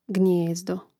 gnijézdo gnijezdo